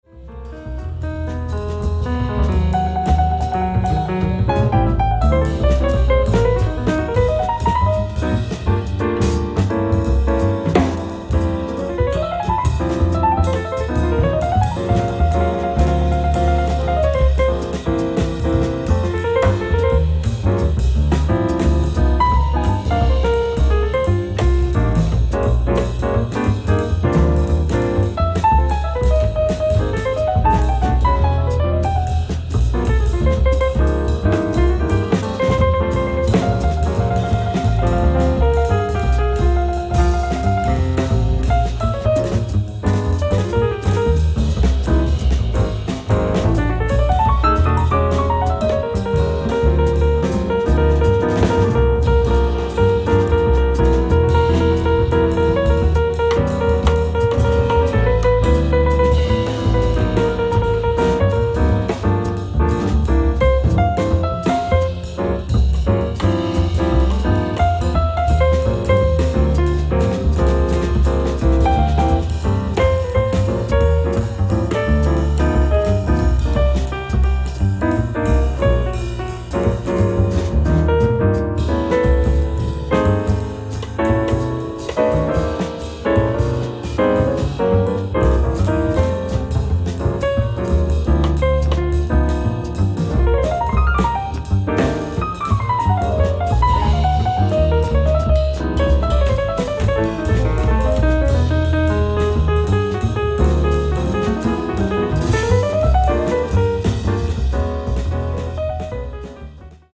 2,500円 ライブ・アット・クィーン・エリザベス・ホール、ロンドン 11/17/2000 イギリスＢＢＣ放送音源！！